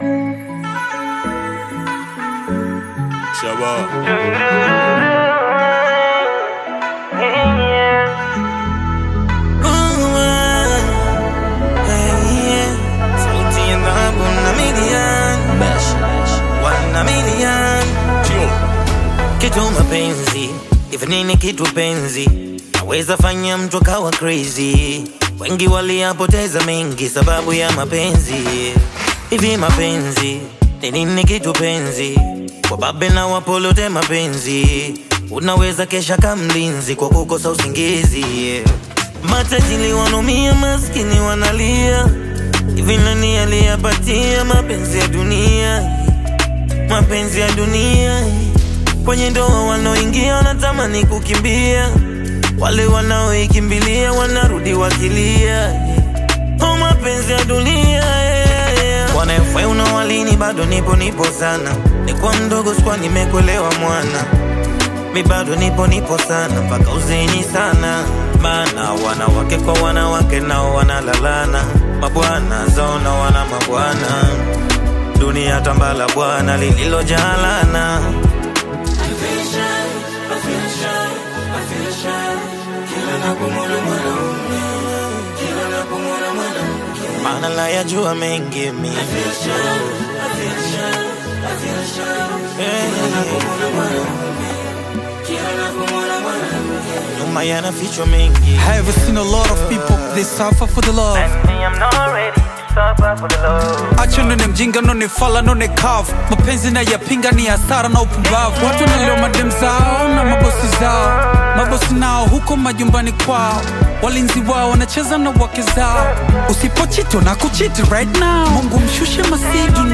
heartfelt melodies and expressive vocals
blends modern production with intimate lyricism